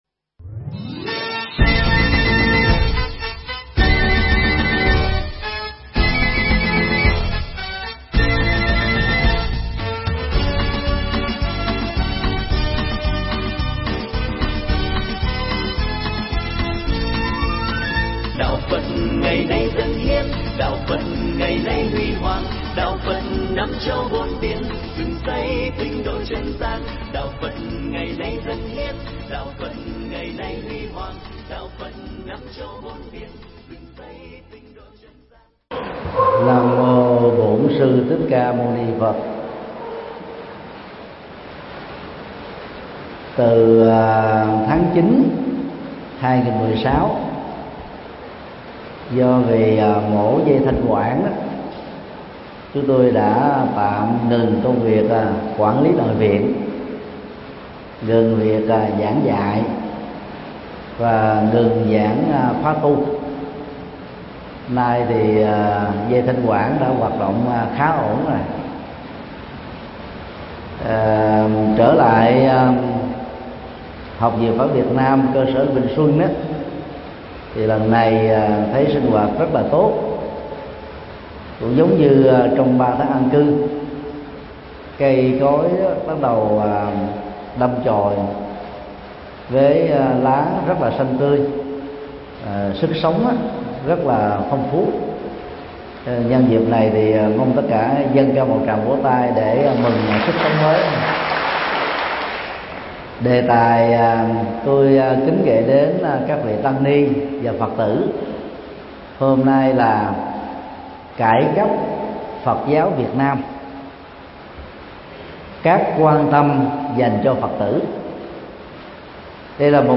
Mp3 Pháp Thoại Cải Cách Phật Giáo Việt Nam- Các Quan Tâm Dành Cho Phật Tử
giảng tại Học viện Phật Giáo Việt Nam (cơ sở Lê Minh Xuân)